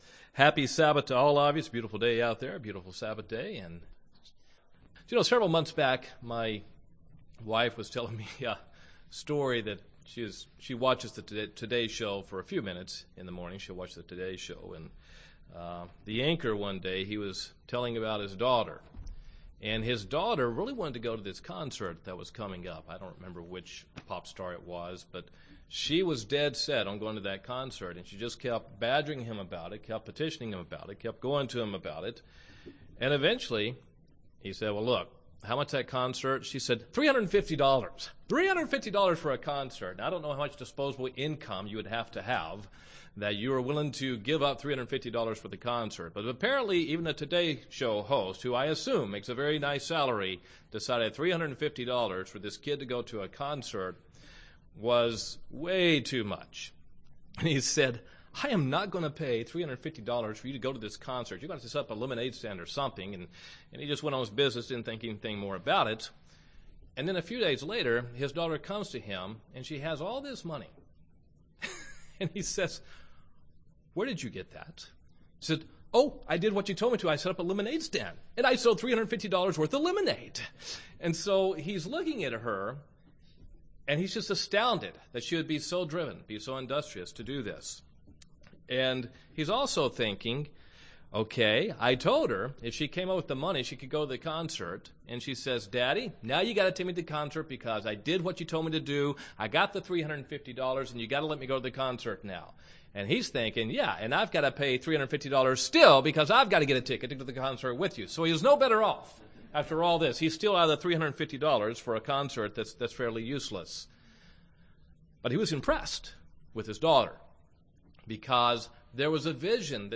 Sermons
Given in Gadsden, AL